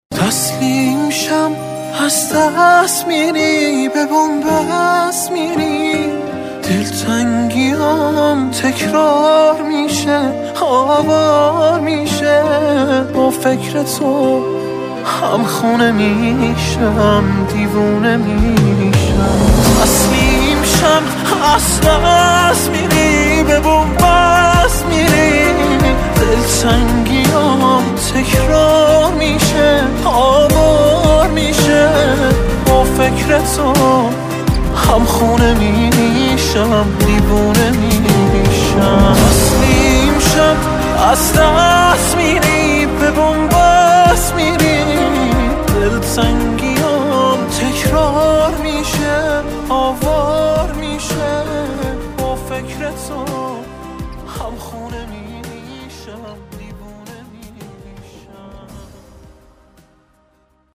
زنگ موبایل رمانتیک و با کلام